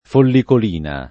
[ follikol & na ]